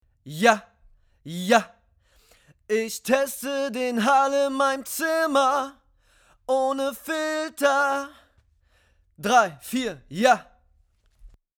Wenn ich in meinem Homestudio sehr laut singe hört man (trotz Absorbern) noch etwas Hall, den ich minimieren möchte.
Bin mir aber nicht sicher ob der Klang unter dem Reflexion Filter leidet (dumpfer).